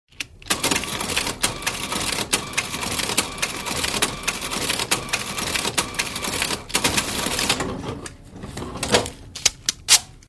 Звуки кассы
Звук печати чека на старом кассовом аппарате и резкое обрывание